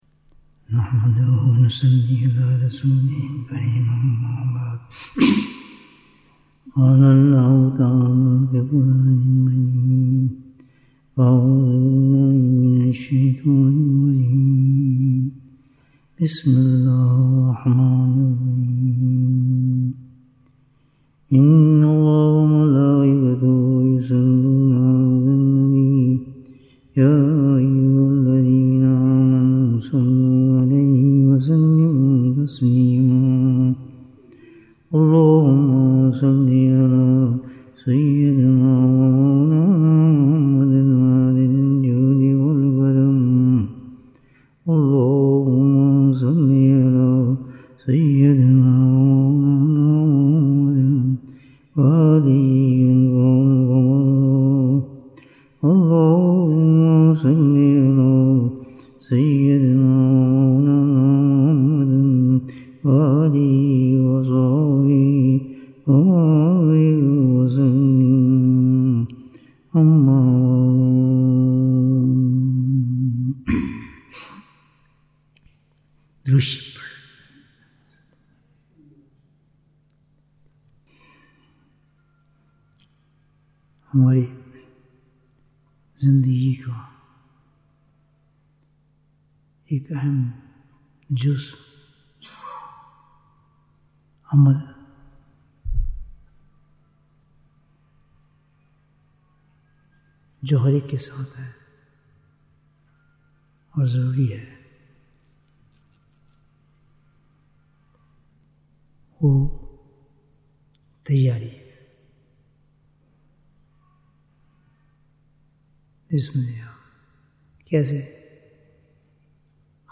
زوال سے پہلے نعمتوں کی قدر کرو Bayan, 110 minutes6th November, 2025